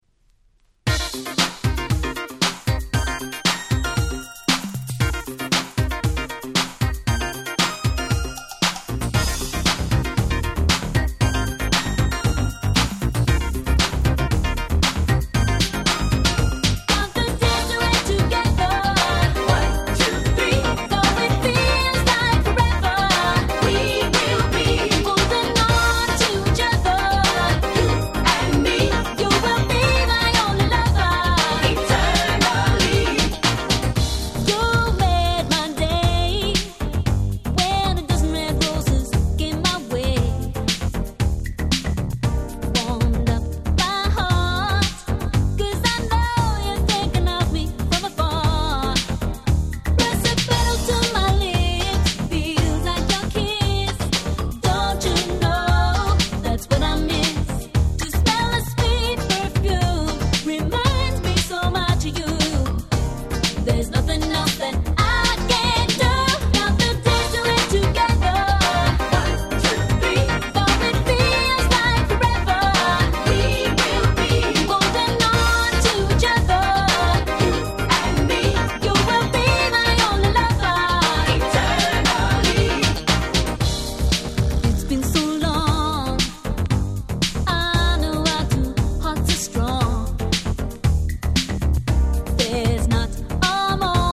90' Nice R&B LP !!